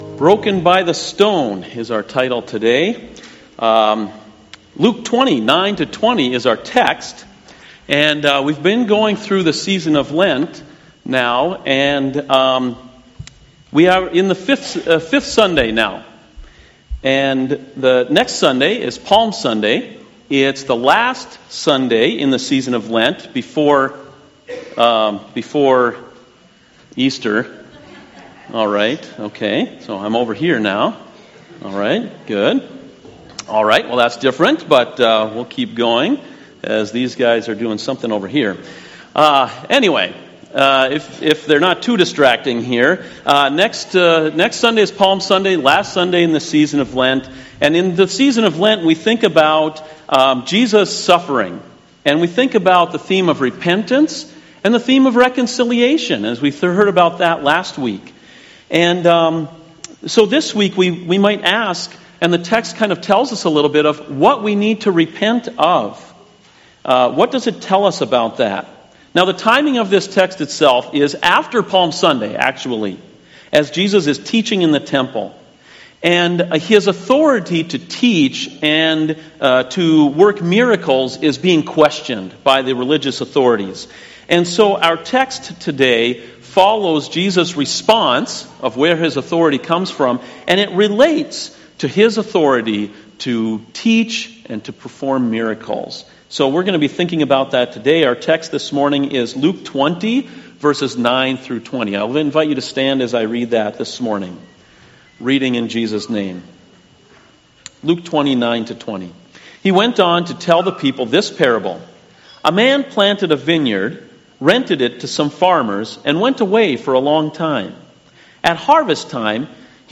CoJ Sermons